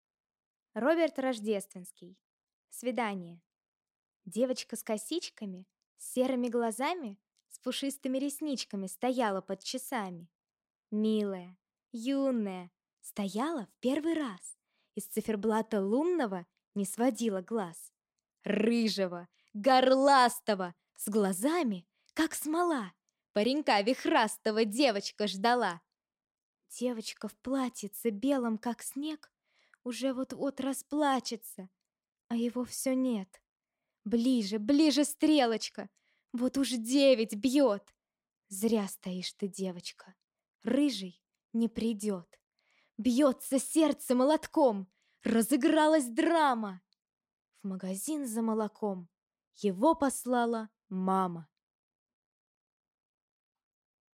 Демо голоса.